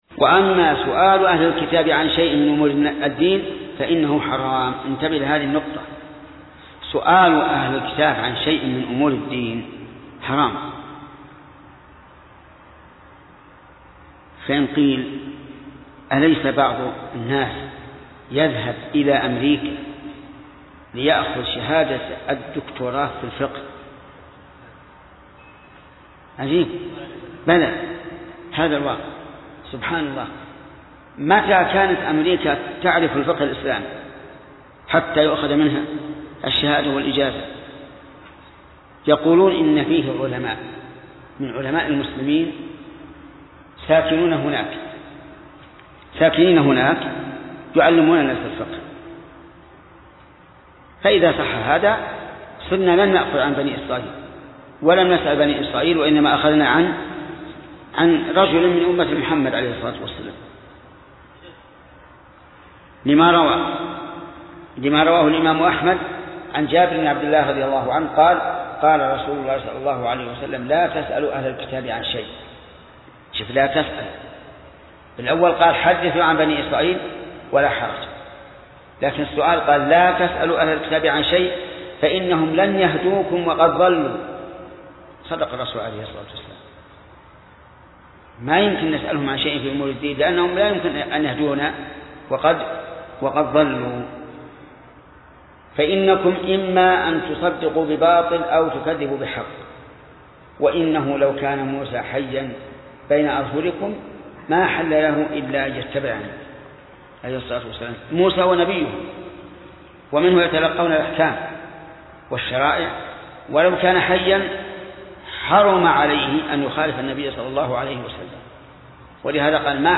درس :18: من صفحة: 359 ، قوله: وأما سؤال أهل الكتاب، إلى صفحة: 379 ، قوله: وله فوائد كثيرة تظهر بحسب السياق....